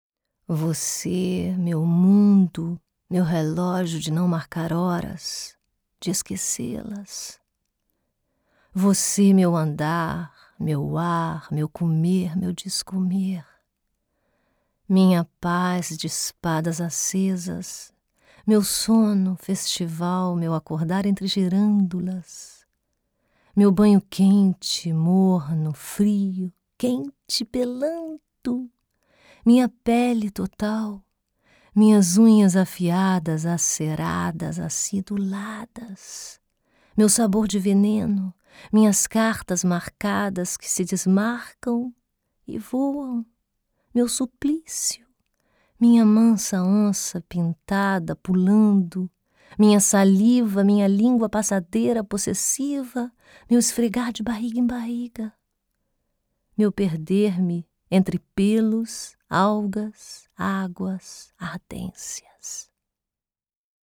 Portugiesich/Brasilianische Sprachaufnahmen von professioneller brasilianischer Schauspielenrin für Image, VO, Werbung und Ansagen.
Sprechprobe: eLearning (Muttersprache):